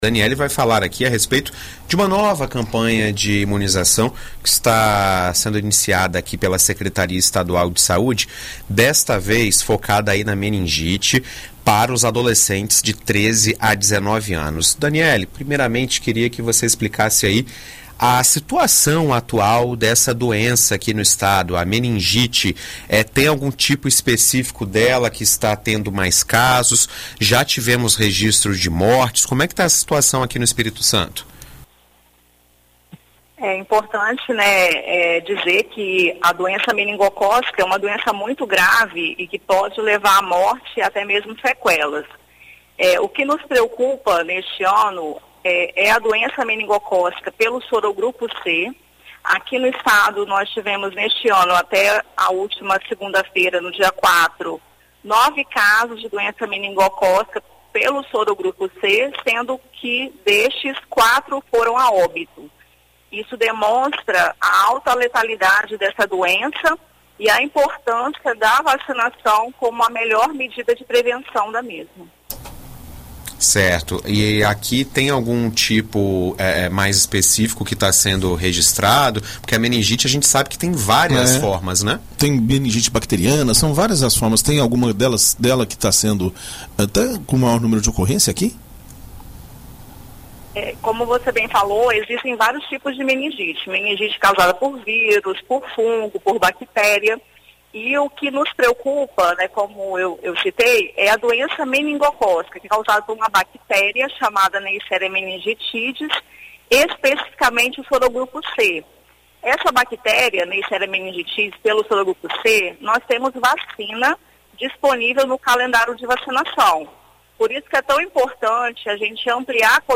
Em entrevista à BandNews FM Espírito Santo nesta terça-feira (12)